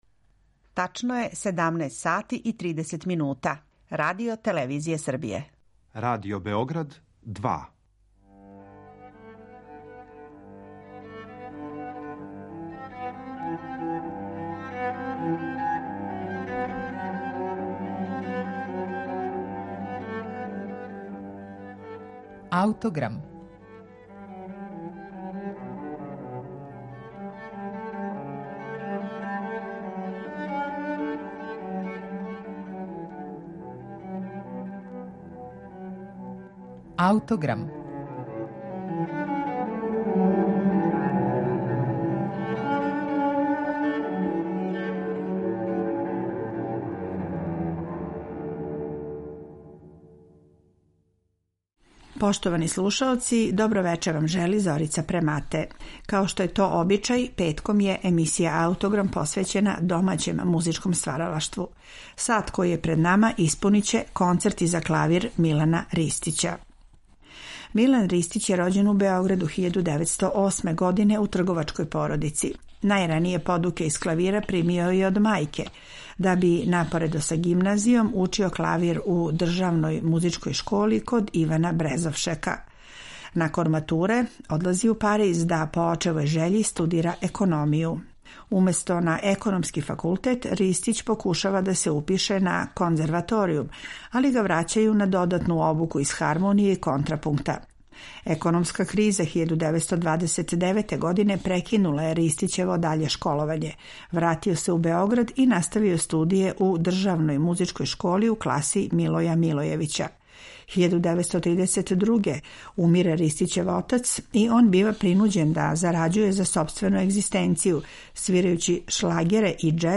Овога пута слушаћете два клавирска концерта нашег композитора из прошлог века, члана прослављене „прашке групе" и академика - Милана Ристића.